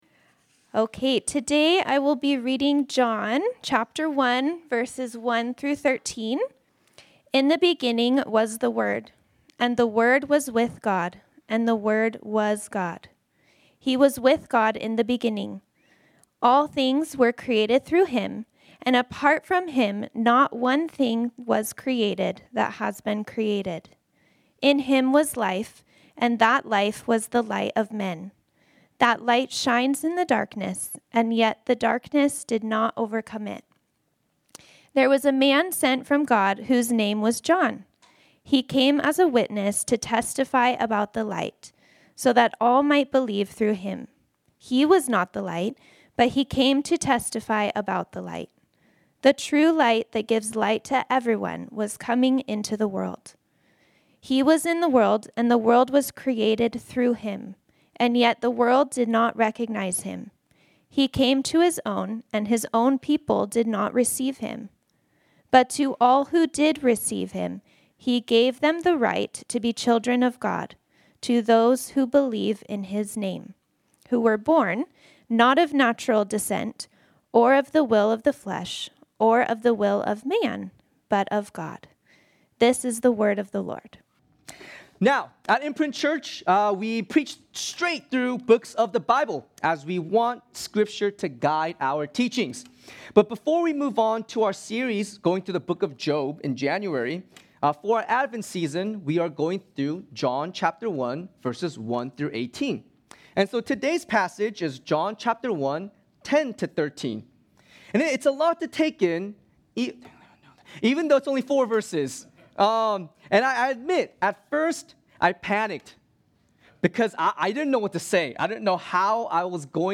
This sermon was originally preached on Sunday, December 21, 2025.